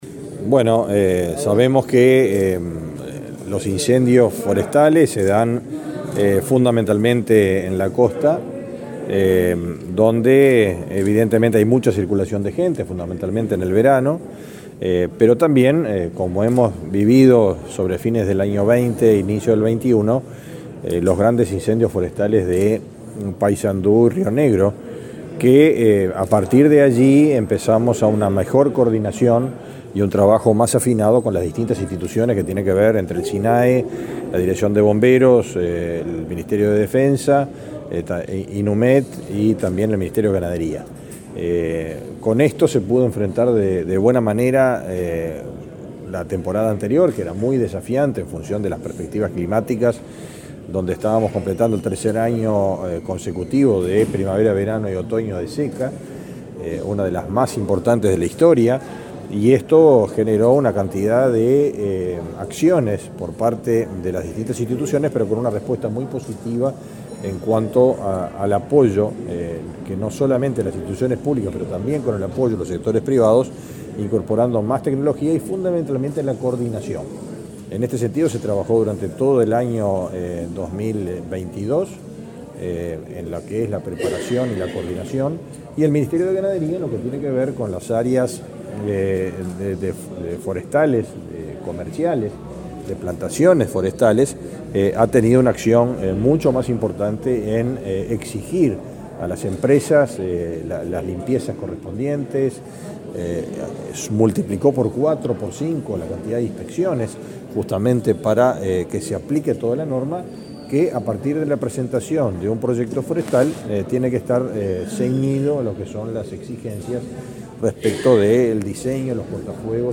Declaraciones del ministro de Ganadería, Fernando Mattos